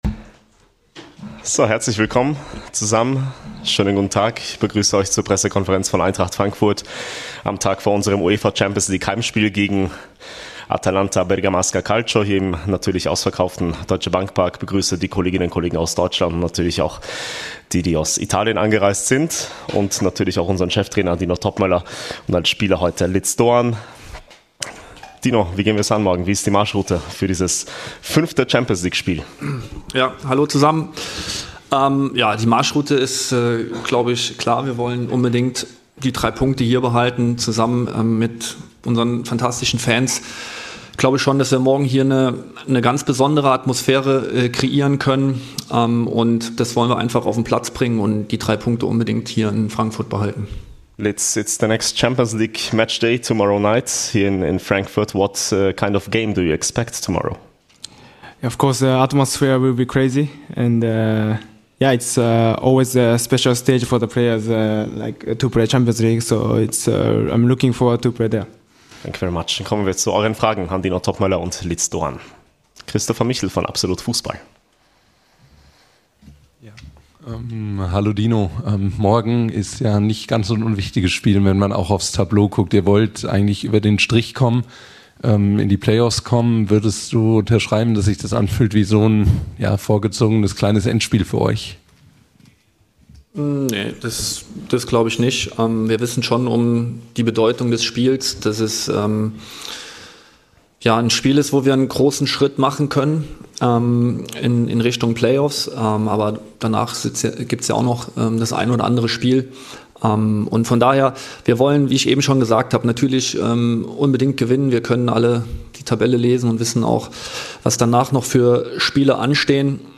Cheftrainer Dino Toppmöller und Ritsu Doan sprechen im Vorfeld des Champions-League-Heimspiels gegen Atalanta Bergamo mit den Medienvertretern.